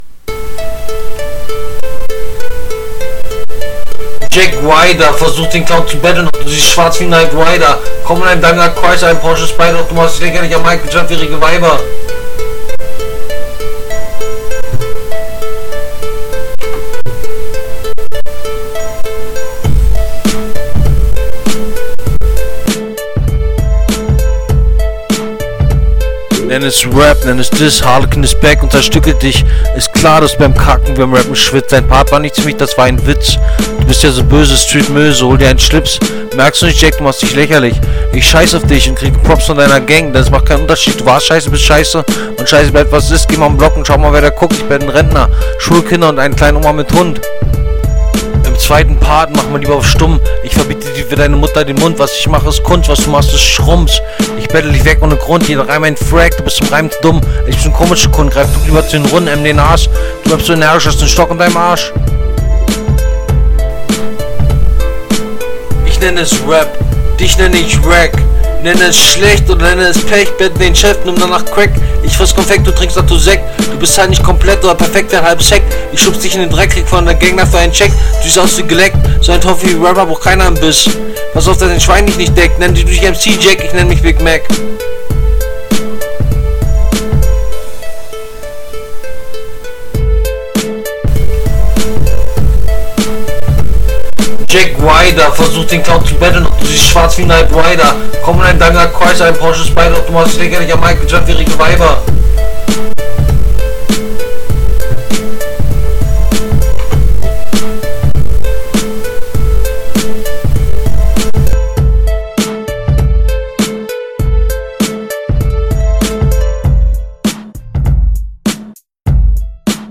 Qualität fickt mein Gehör aber richtig.